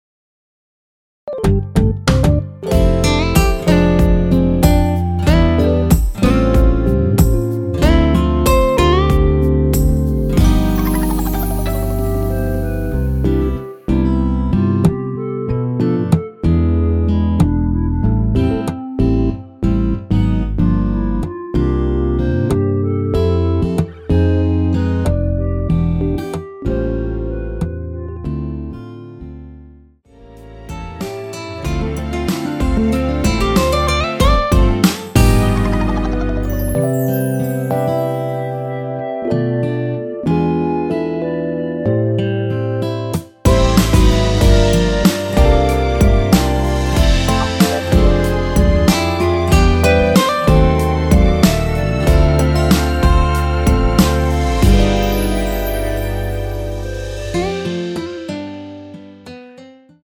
원키에서(-1)내린 멜로디 포함된 MR입니다.
멜로디 MR이라고 합니다.
앞부분30초, 뒷부분30초씩 편집해서 올려 드리고 있습니다.
중간에 음이 끈어지고 다시 나오는 이유는